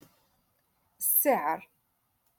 Moroccan Dialect - Rotation Two- Lesson Fifty One